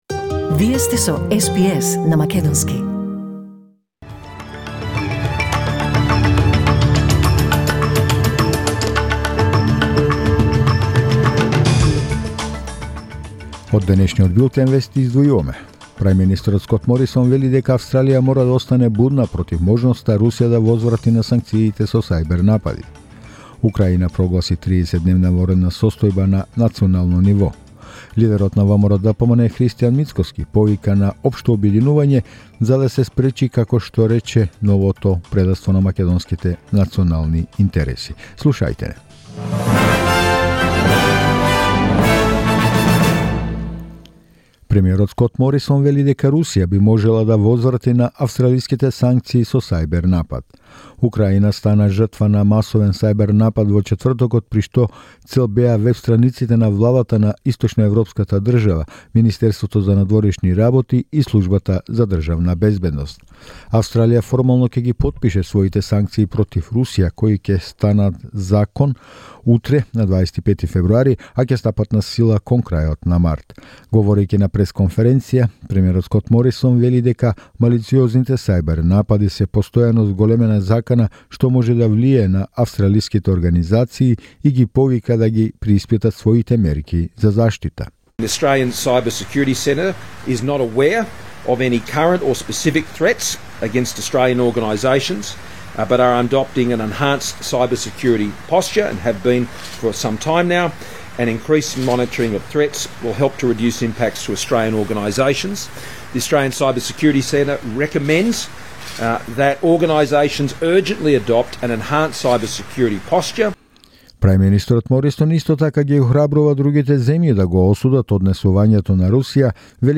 SBS News in Macedonian 24 February 2022